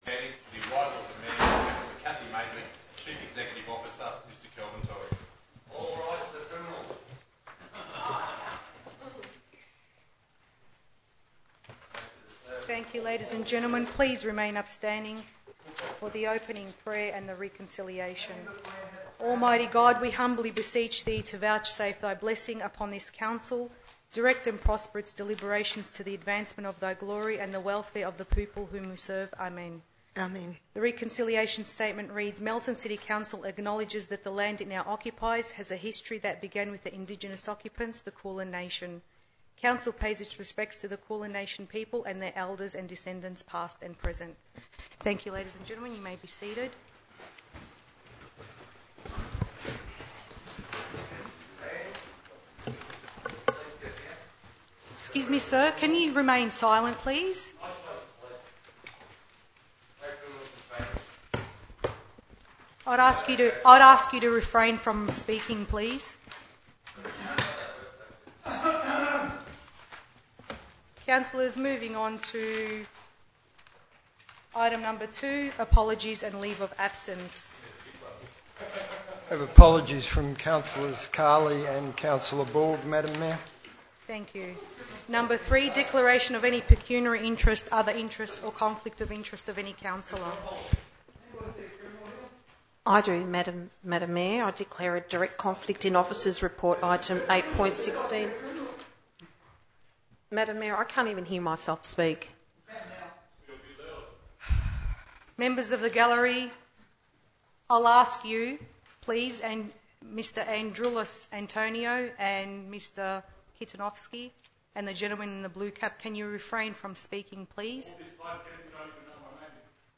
23-7-13_council_meeting.mp3